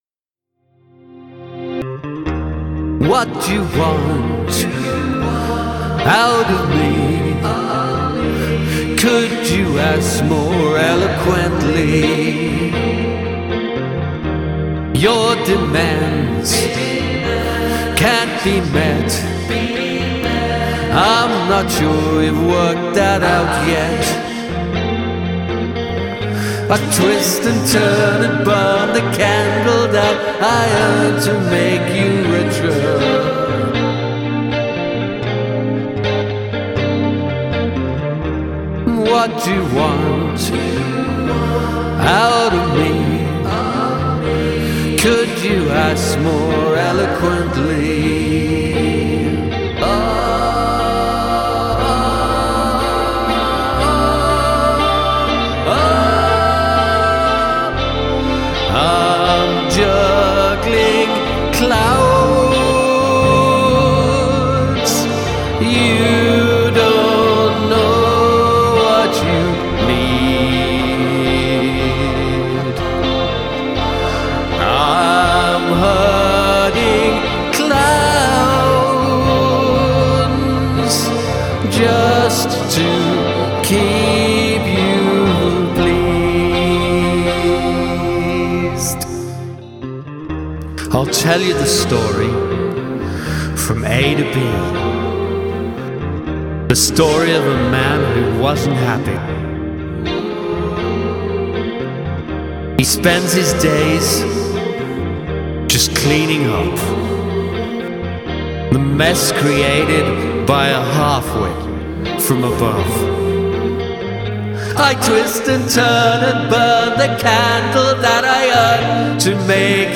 The near-dissonance in the jiggling clouds refrain works really well to create the tension implied by the lyric.
Fantastic chorus sound on both the guitar and vocals. Really love the call and response thing that's going on.
I really like the background vocals, like a rhythm track!
Great break with speaking part!
amazing harmonies, epic production, love that big shift- very very nicely done, ‘herding clowns’ is my fave 👏
Love the harmonic echos.